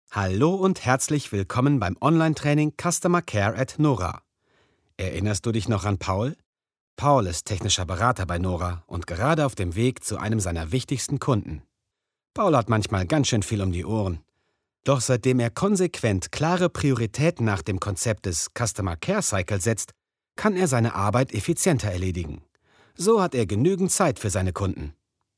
Jung - dynamisch - voll - warm - variable
Sprechprobe: eLearning (Muttersprache):
dynamic - full - warm